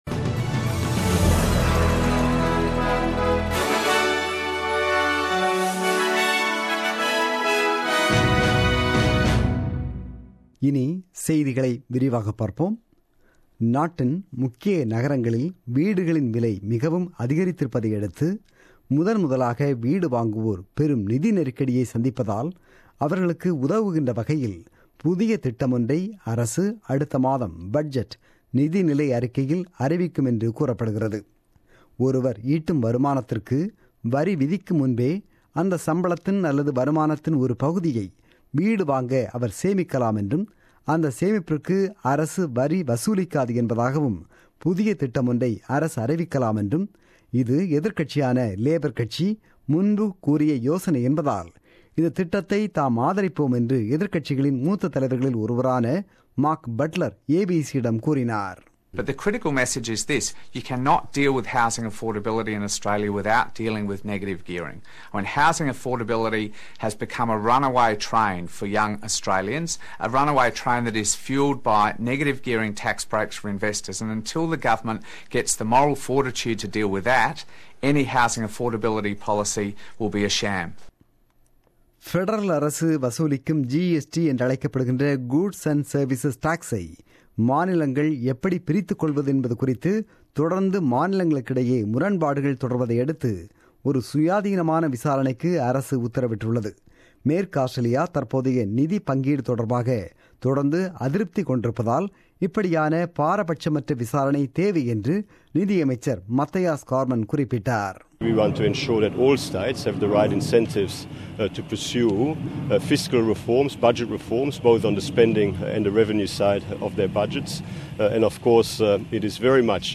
The news bulletin broadcasted on 30 April 2017 at 8pm.